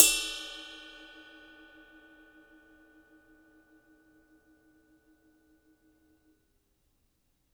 ride bell.WAV